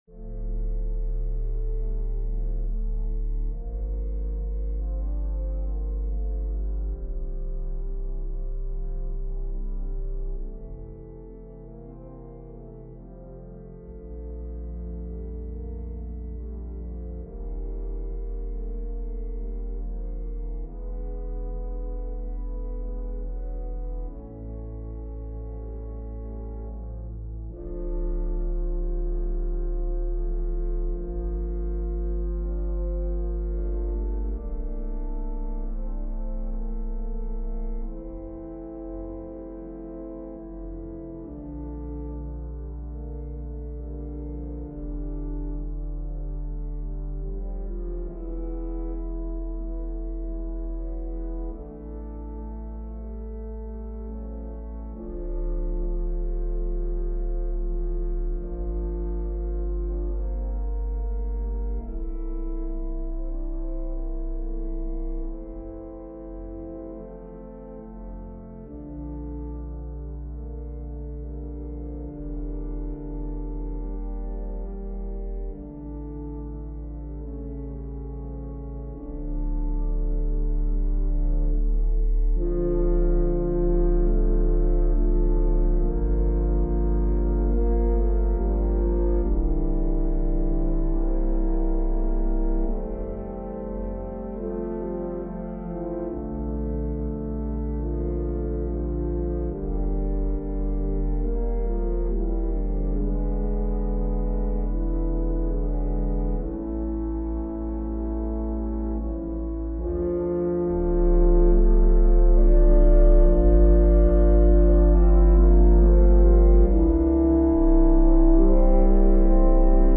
An organ solo version